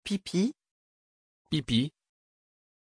Pronunciation of Pippi
pronunciation-pippi-fr.mp3